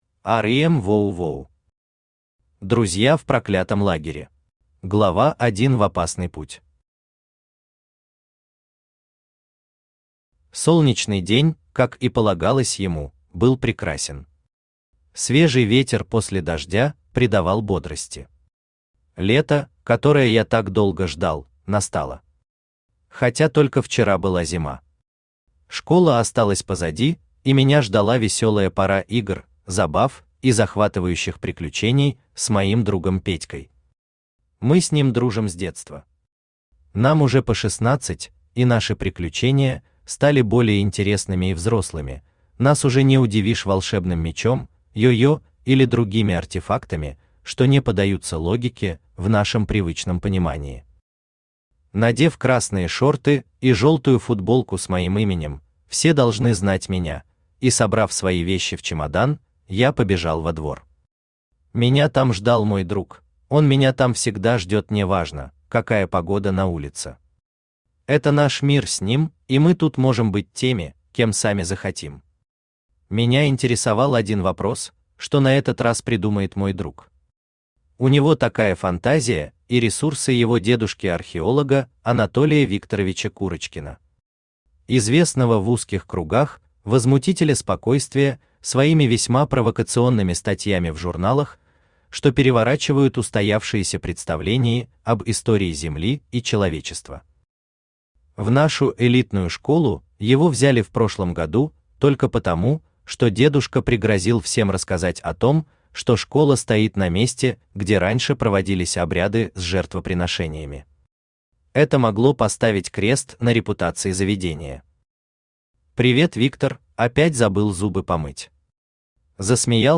Аудиокнига Друзья в проклятом лагере | Библиотека аудиокниг
Читает аудиокнигу Авточтец ЛитРес.